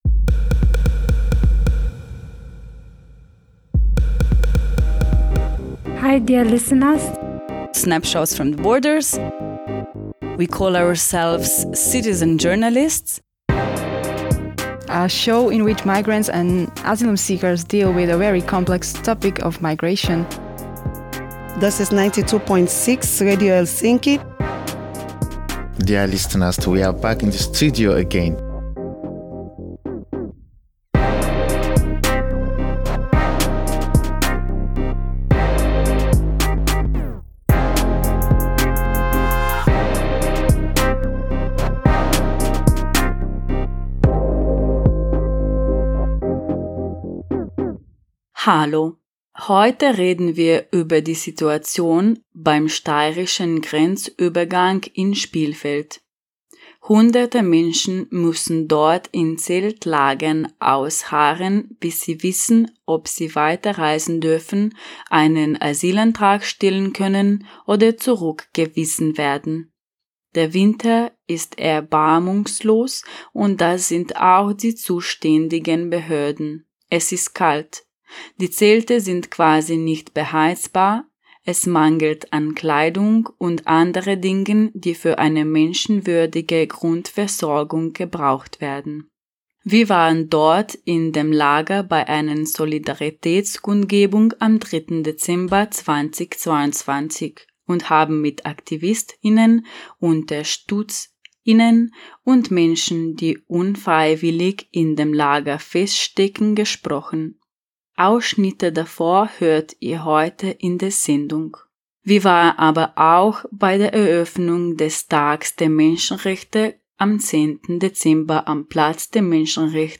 Beschreibung vor 3 Jahren Heute senden wir einen Schwerpunkt zur Situation von people on the move an europäischen Binnengrenzen. Zuerst hört ihr von Tag der Menschenrechte in Graz.